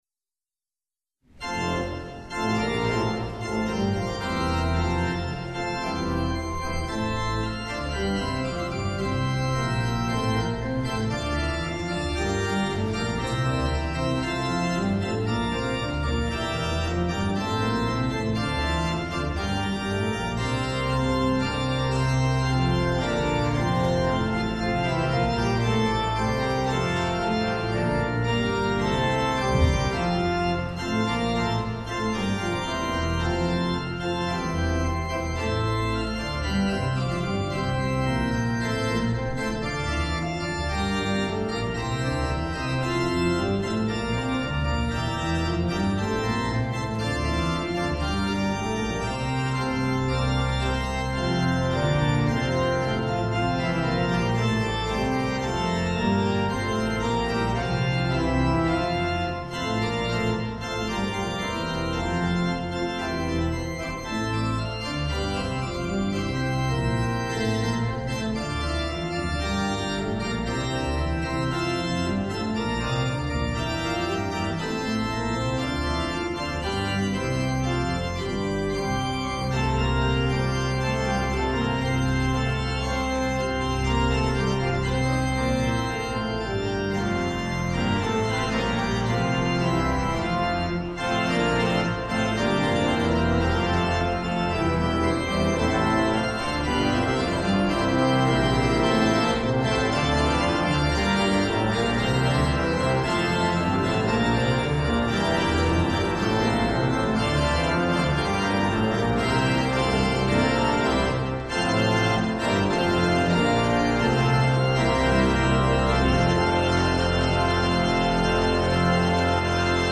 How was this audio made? Hear the Bible Study from St. Paul's Lutheran Church in Des Peres, MO, from December 1, 2024. Join the pastors and people of St. Paul’s Lutheran Church in Des Peres, MO, for weekly Bible study on Sunday mornings.